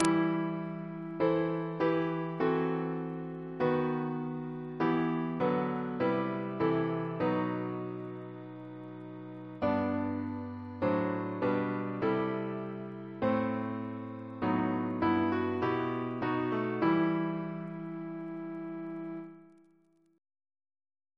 Double chant in E minor Composer: John Davy (1763-1824), Composer of songs Reference psalters: H1982: S431